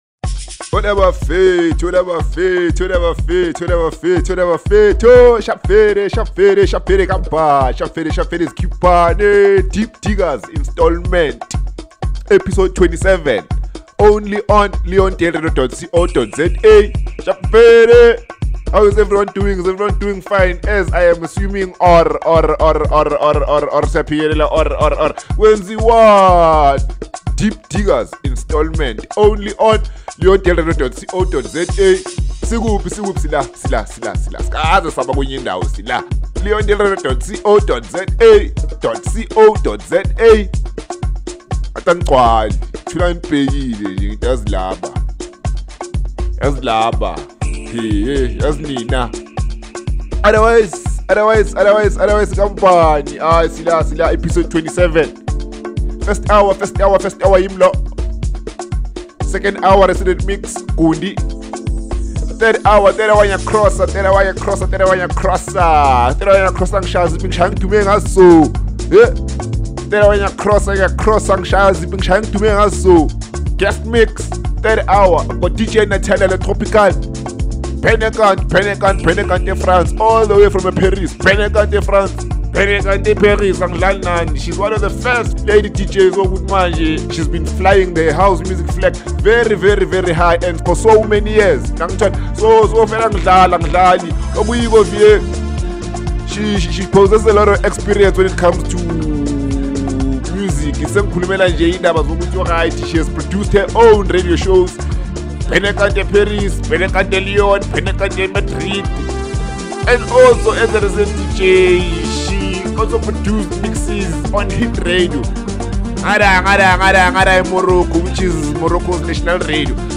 UNMIXED SELECTIONS